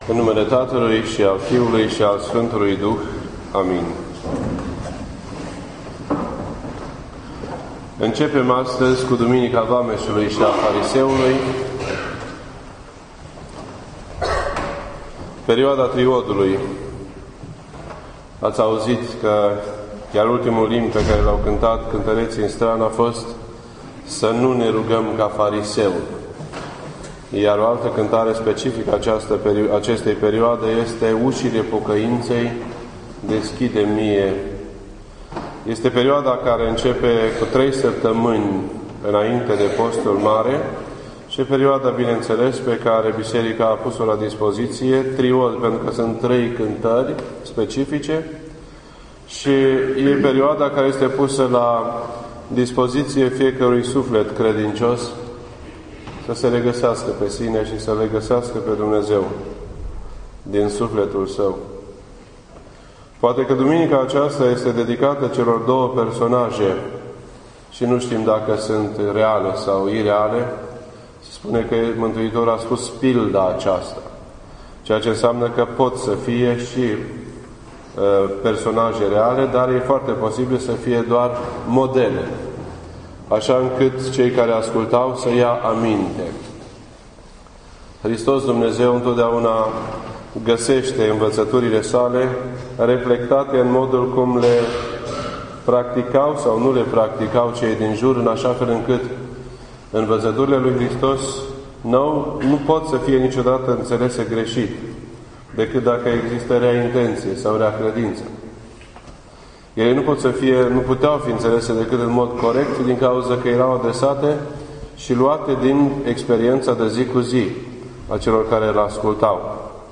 This entry was posted on Sunday, February 24th, 2013 at 8:43 PM and is filed under Predici ortodoxe in format audio.